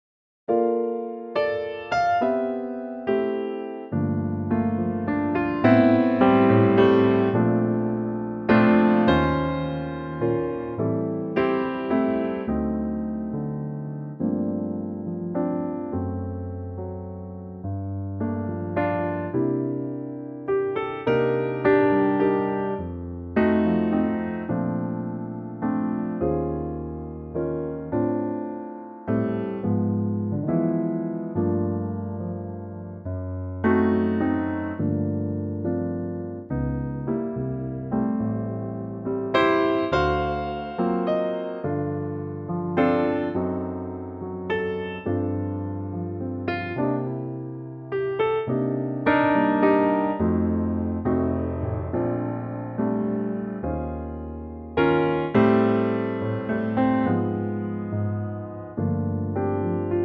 Unique Backing Tracks
key - F - vocal range - C to D
in a lovely piano only arrangement.